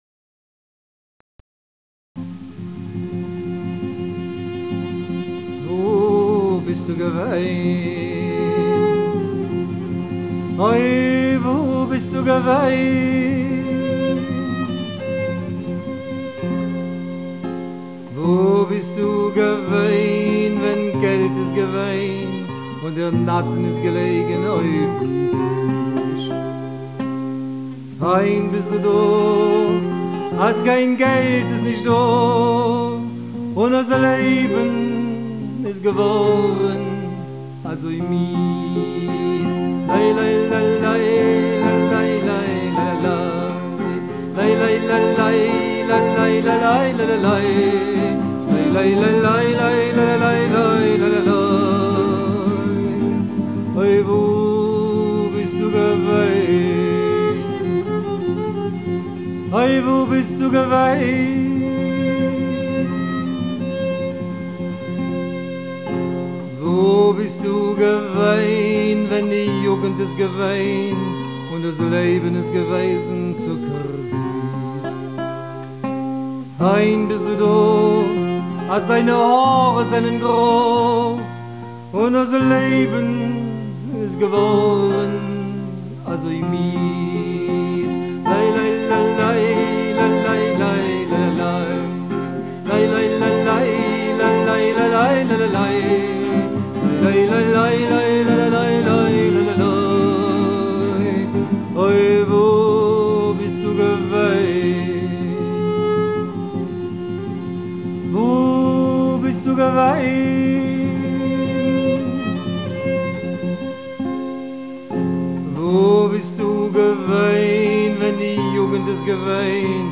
Die Lieder
Fast alle Melodien sind in Molltonarten.
Jiddisch